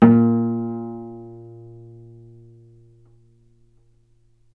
vc_pz-A#2-ff.AIF